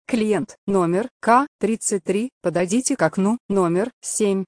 Звуки электронной очереди
На этой странице собраны звуки электронной очереди — знакомые сигналы, голосовые объявления и фоновый шум.